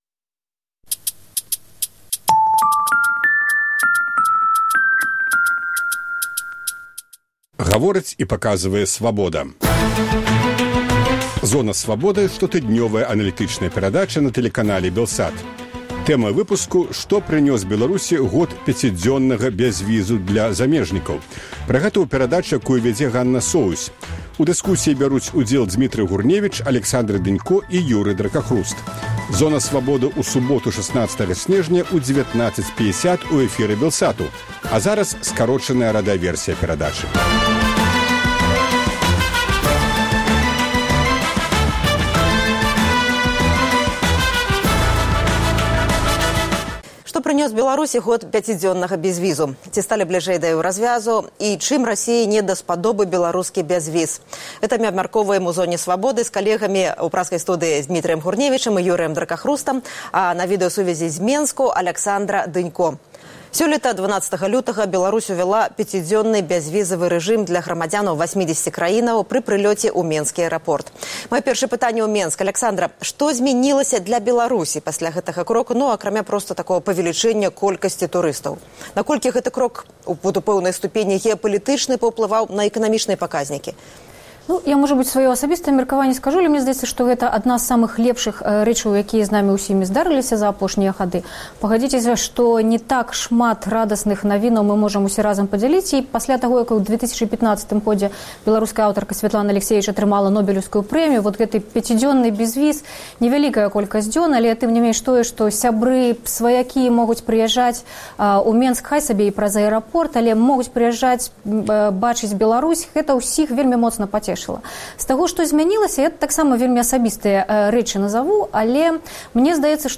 А зараз – скарочаная радыёвэрсія перадачы.